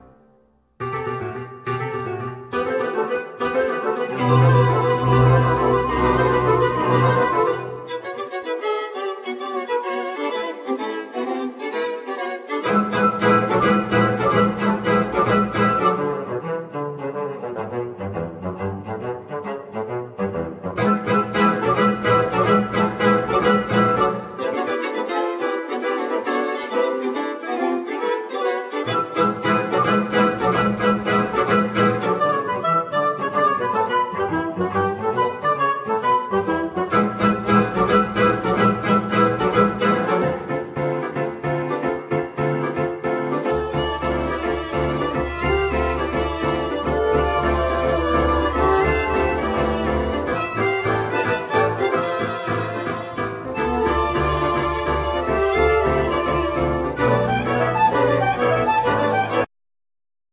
Vocals
Flute,Sax
Clarinet
Basoon
Piano,Flute
Violin,Trombone
Viola
Cello
Double bass